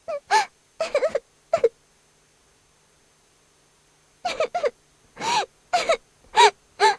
小姑娘抽泣声音效_人物音效音效配乐_免费素材下载_提案神器